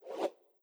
SwooshSlide1b.wav